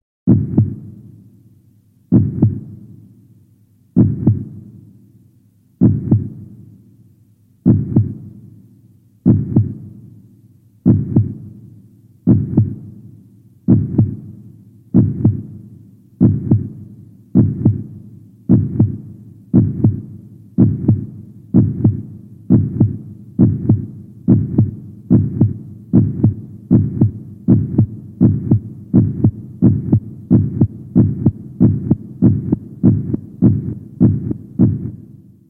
Категория: Различные звуковые реалтоны